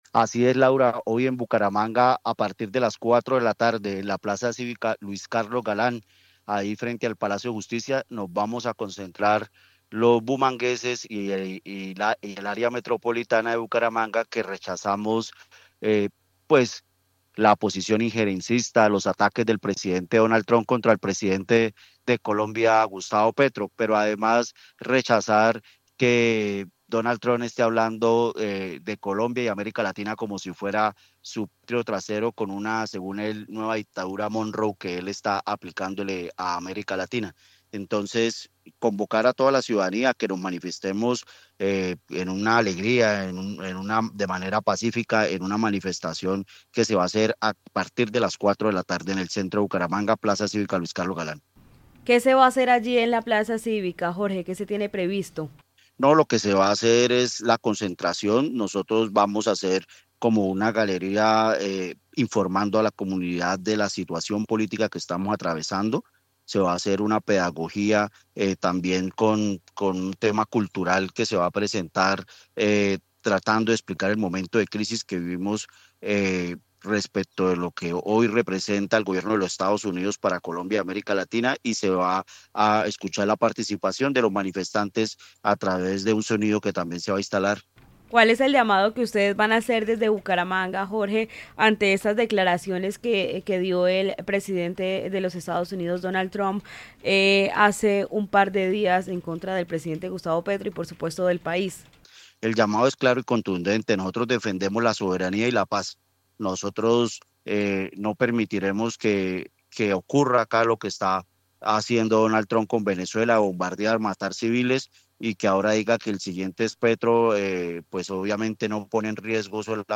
Jorge Flórez, concejal de Bucaramanga